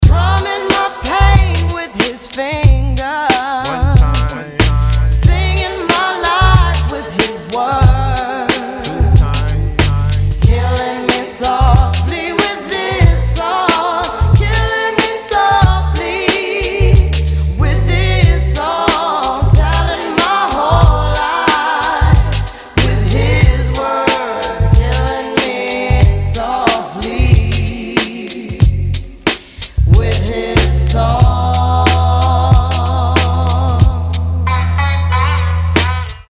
• Качество: 320, Stereo
мужской голос
громкие
женский вокал
Хип-хоп
дуэт
soul